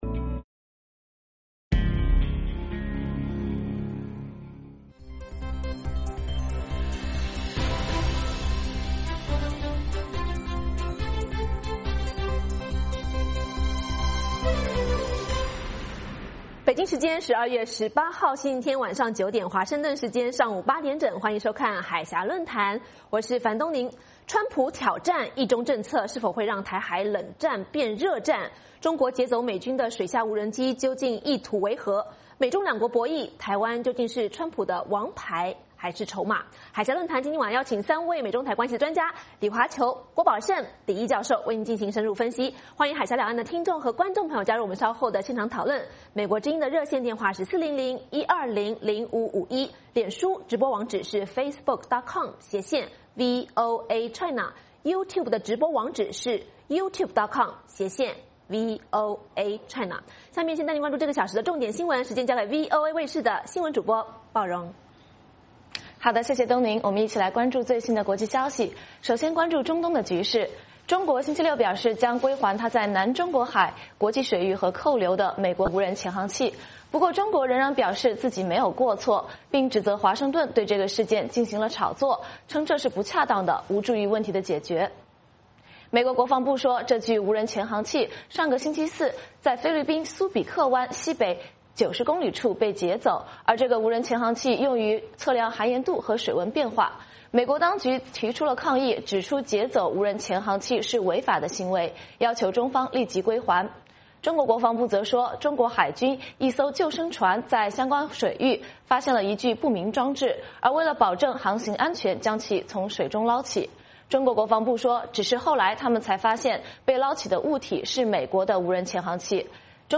《海峡论谈》节目邀请华盛顿和台北专家学者现场讨论政治、经济等各种两岸最新热门话题。